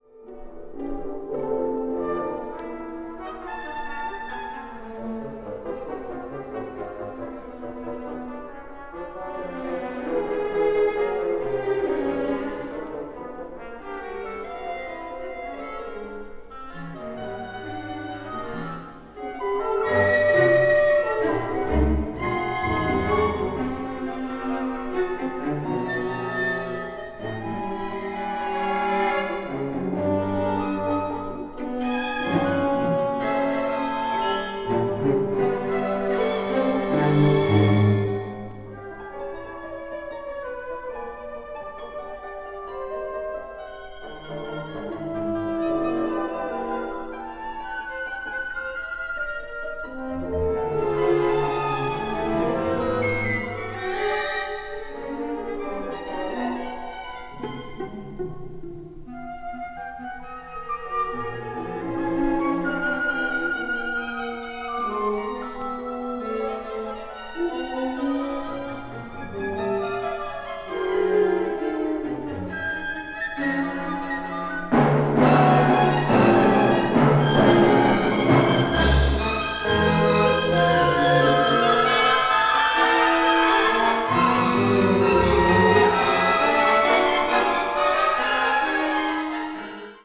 media : EX/EX(わずかにチリノイズが入る箇所あり)
20th century   contemporary   orchestra   organ   serialism